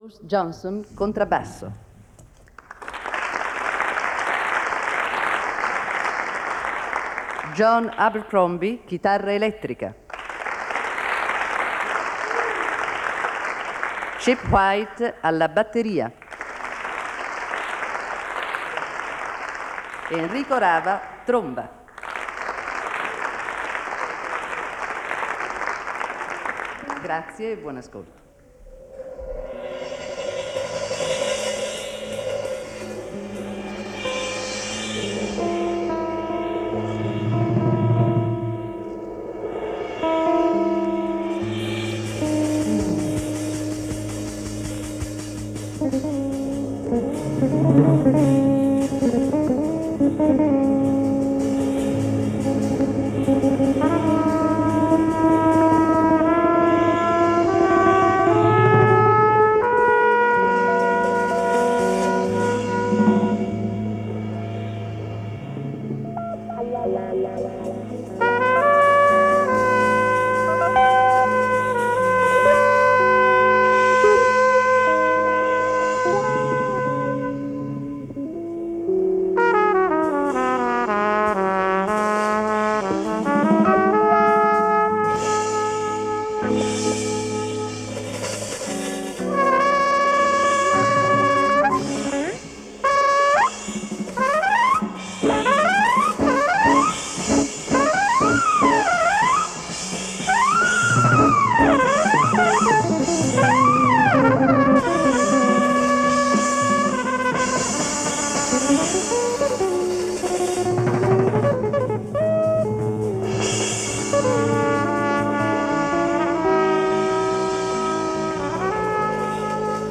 guitar
bass
drums
Avant garde Jazz from Italy is alive and very well.